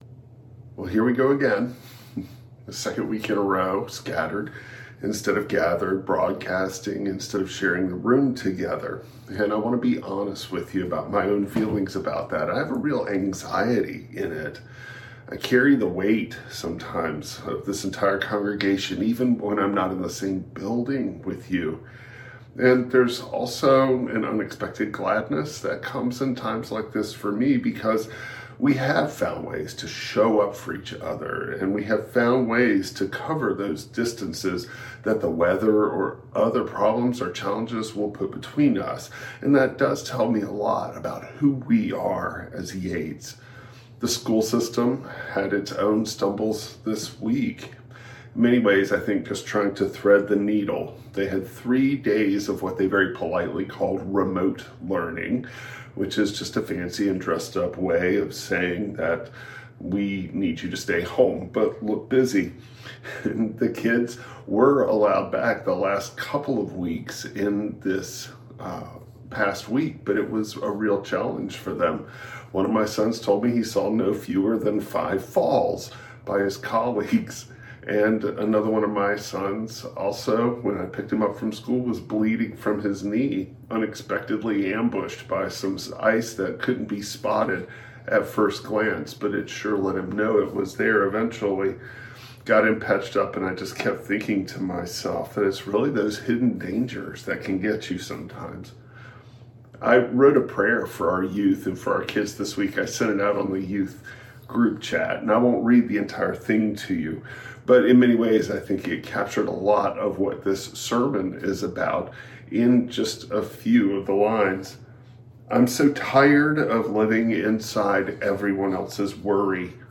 A sermon on Matthew 5:1–12 exploring how Jesus names God’s nearness in places we never call a blessing—and how these blessings shape our discipleship.
Matthew 5:1-12 Service Type: Traditional Service Jesus names and embodies God’s nearness in the very places we would never call a blessing.